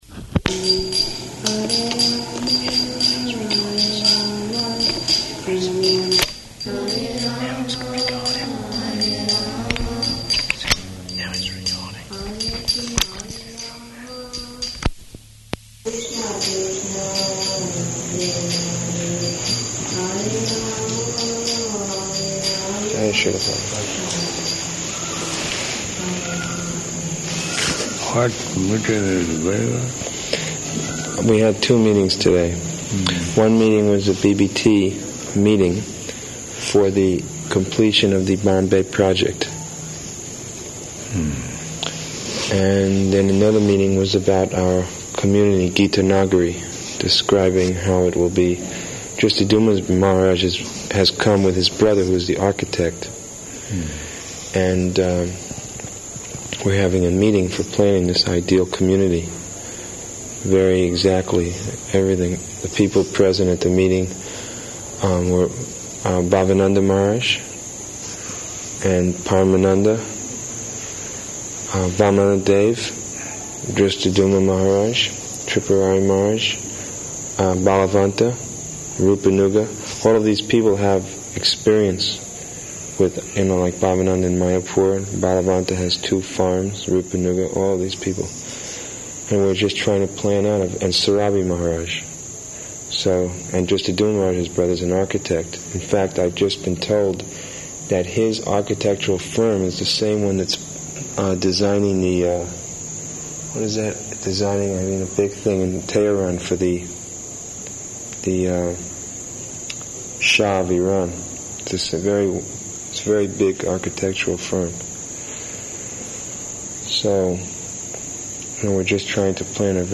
Room Conversation
-- Type: Conversation Dated: October 11th 1977 Location: Vṛndāvana Audio file